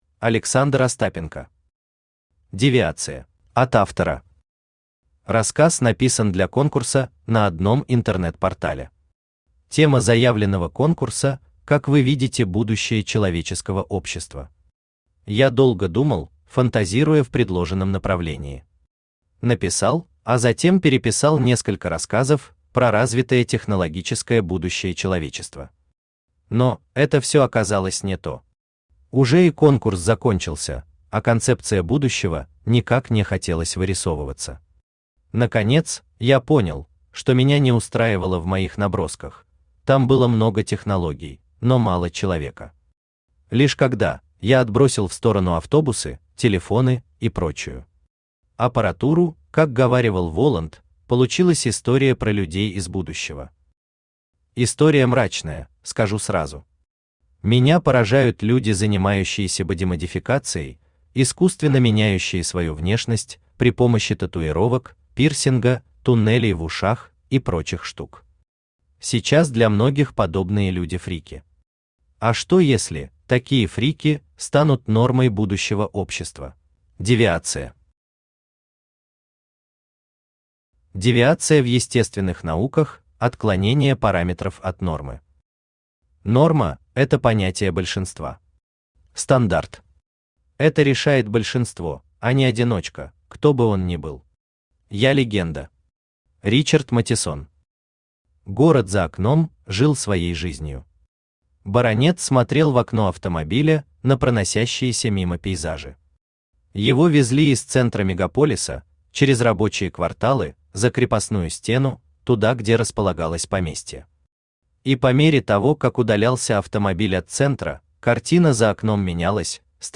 Aудиокнига Девиация Автор Александр Викторович Остапенко Читает аудиокнигу Авточтец ЛитРес.